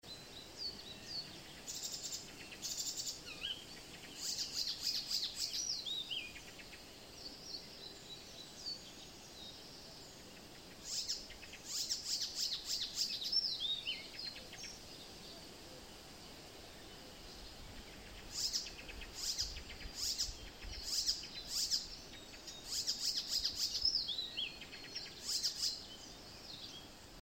Птицы -> Славковые ->
пересмешка, Hippolais icterina
СтатусПоёт